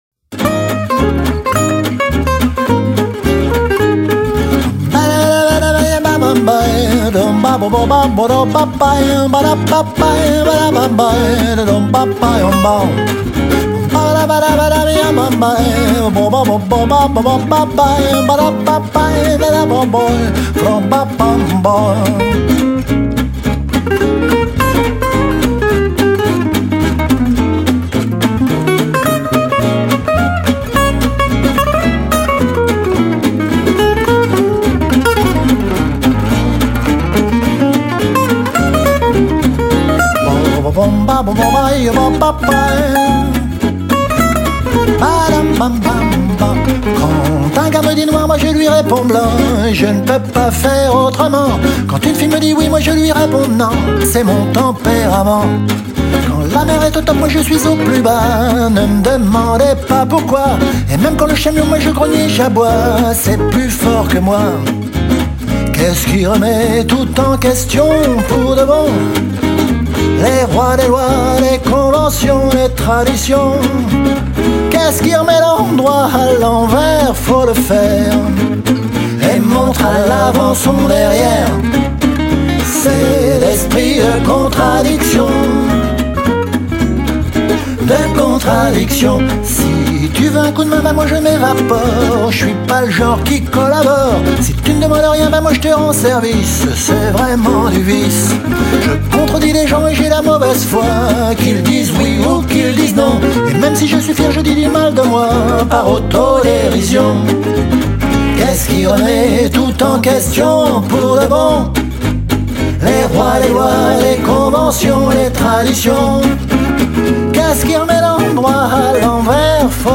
Si vous aimez le swing
les guitares